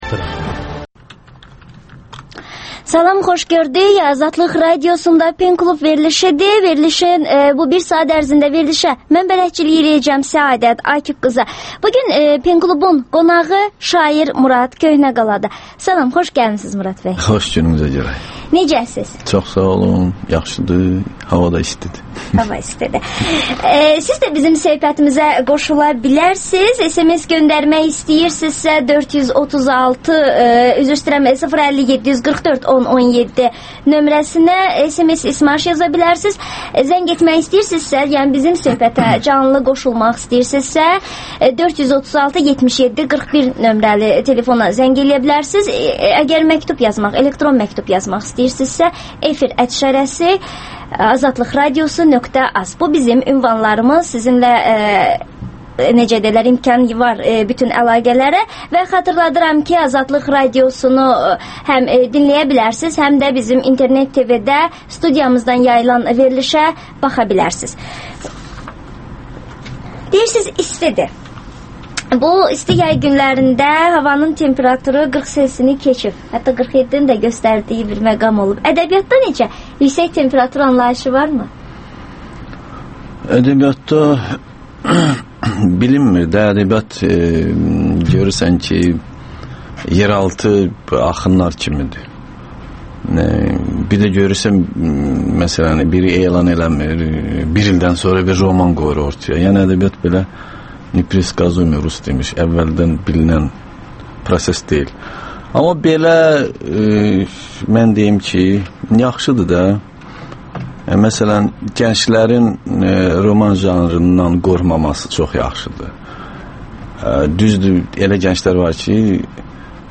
Ədəbiyyat verilişi